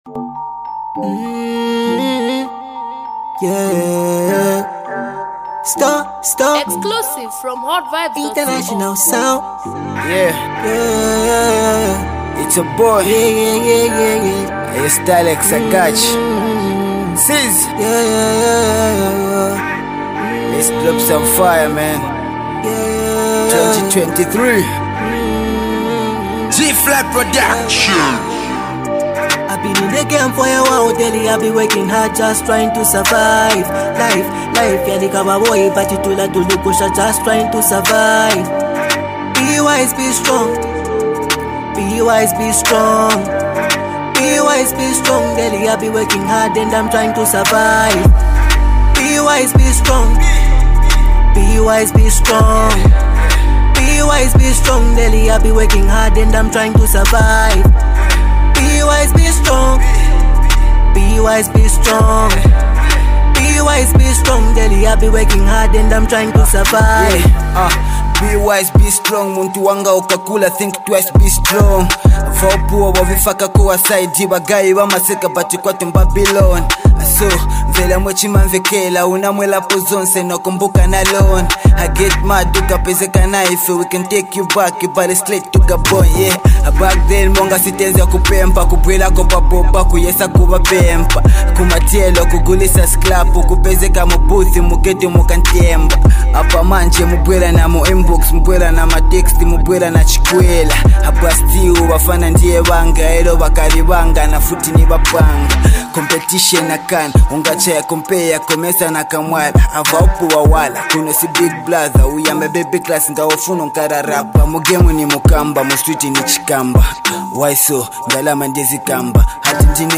heart warming and motivating song
young talented Singer